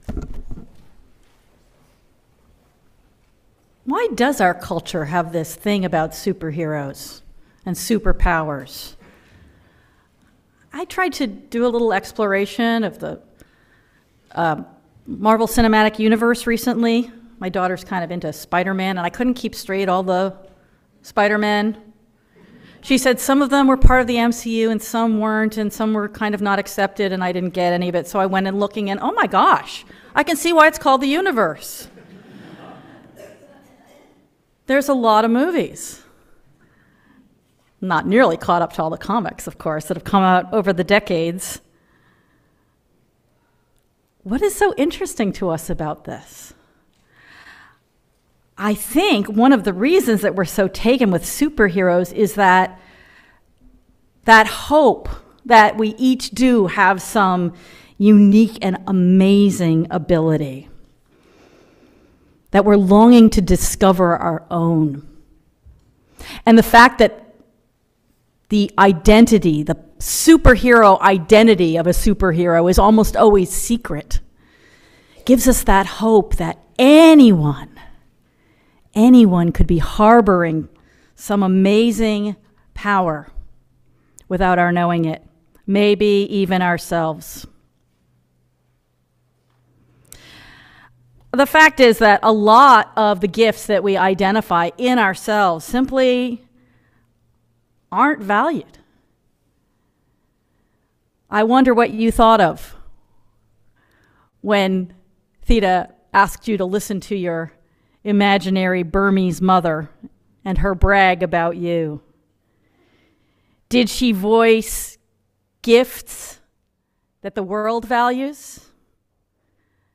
Sermon audio can be found on each service's page (select the service title below), followed by a video of the full service if available (starting April 12, 2020